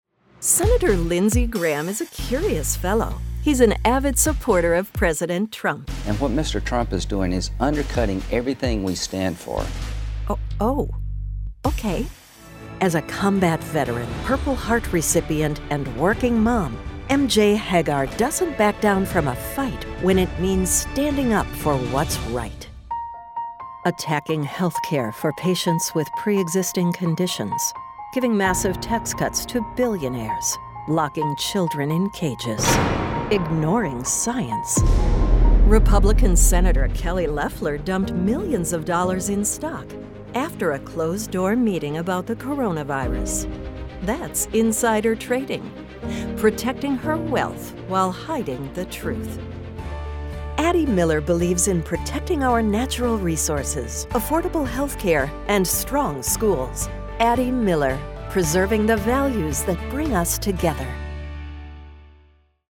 Political